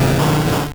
Cri de Saquedeneu dans Pokémon Or et Argent.